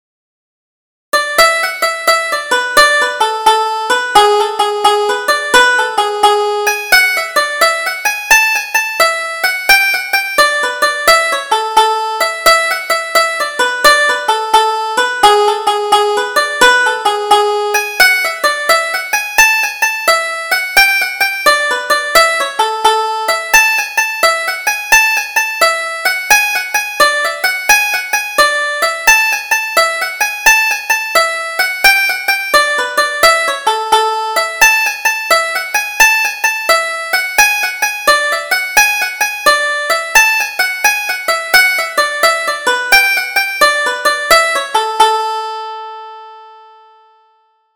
Double Jig: Drive the Cows Home